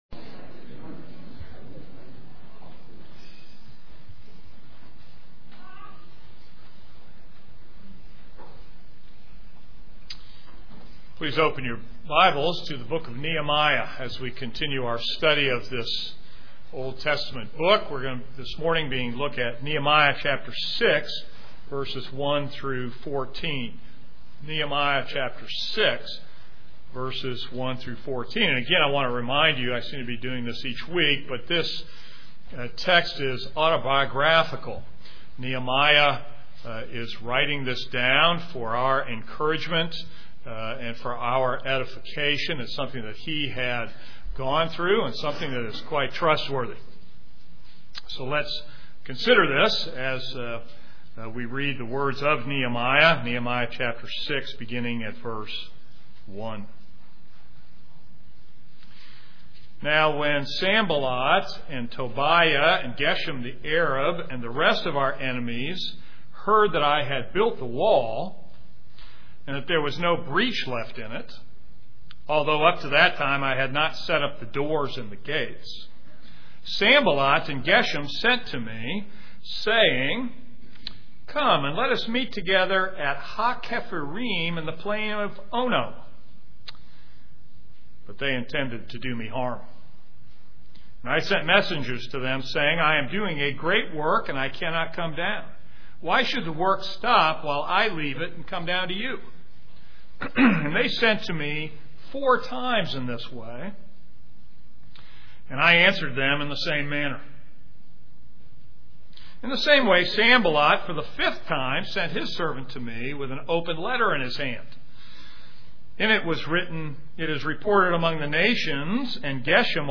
This is a sermon on Nehemiah 6:1-14.